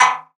Perc [Heavy Metal](1).wav